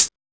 Index of /90_sSampleCDs/USB Soundscan vol.20 - Fresh Disco House I [AKAI] 1CD/Partition C/09-HH CLOSED